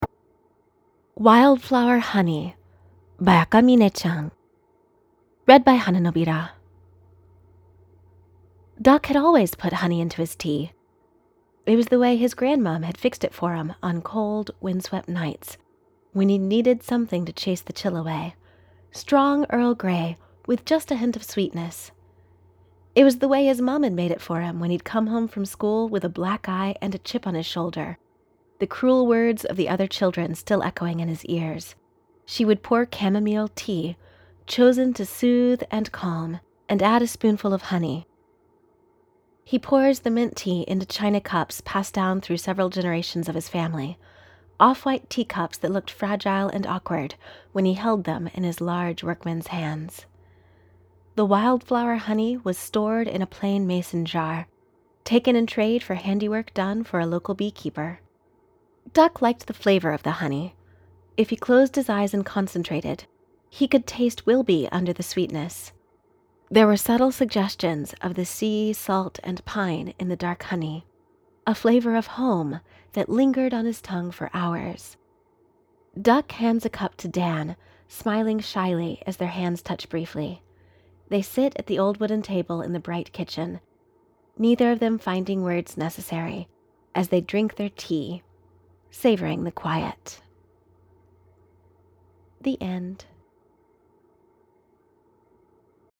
info: collaboration|ensemble , info|improvisational podfic